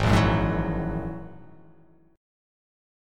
Ab7sus2#5 chord